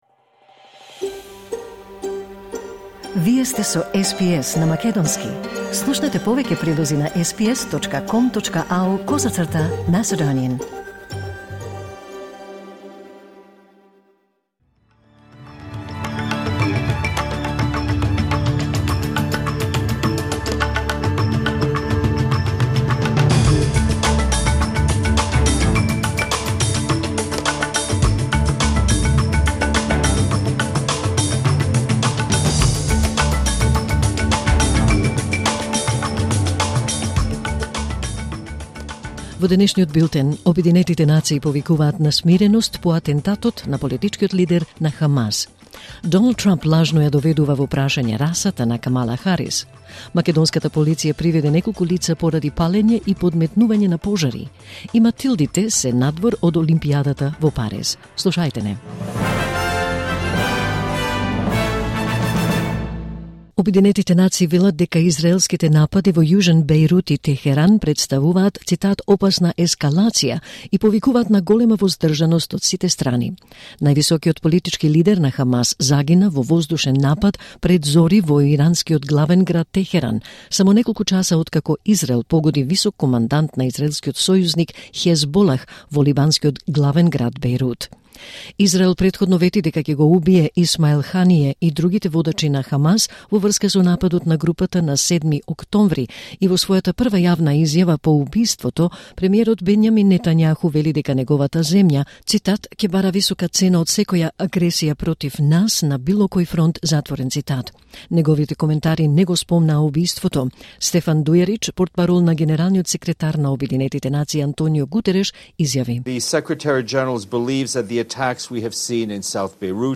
SBS News in Macedonian 1 August 2024